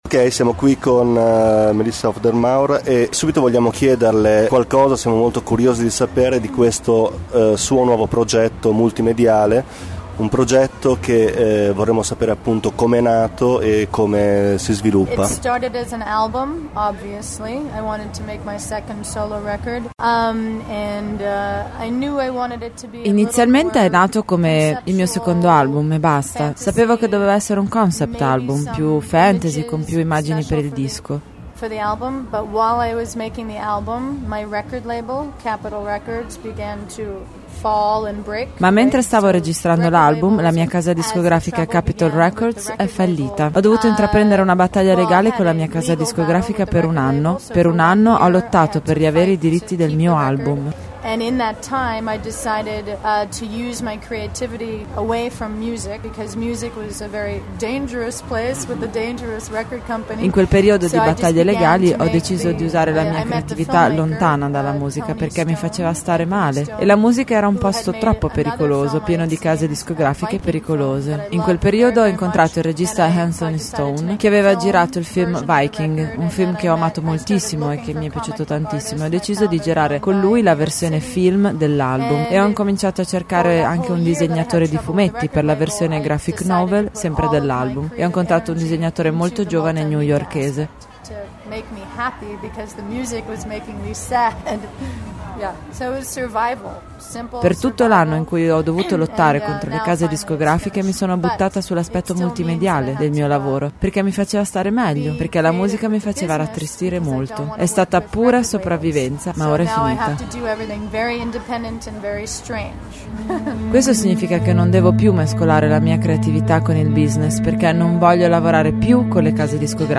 In occasione del suo concerto a Roma, Città del Capo è riuscita ad intervistare la rocker canadese Melissa Auf der Maur. La storica bassista delle Hole ed in seguito degli Smashing Pumpkins sta continuando ormai dal 2004 a portare avanti la sua carriera solista. Nel 2009 dovrebbe finalmente uscire il suo secondo disco, un progetto ambizioso che mette insieme molte delle passioni della ragazza di Montreal.